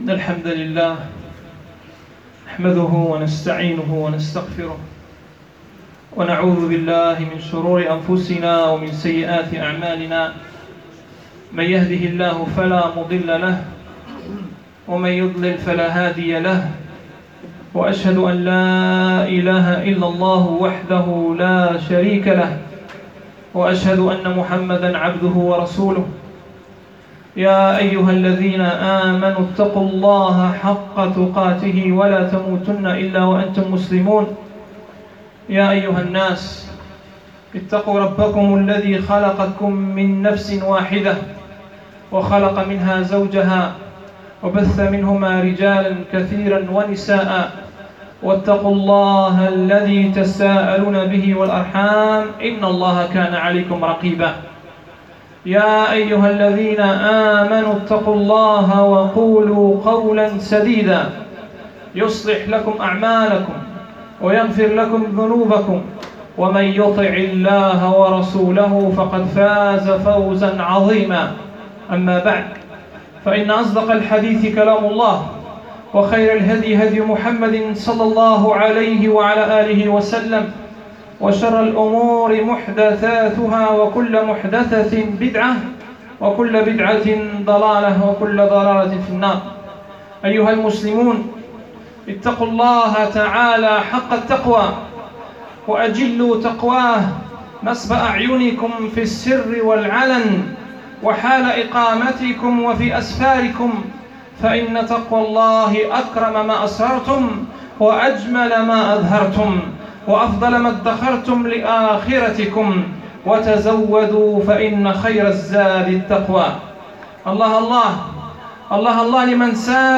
خطبة عيد الأضحى المبارك 1439 هـ الخطب المنبرية بدار القران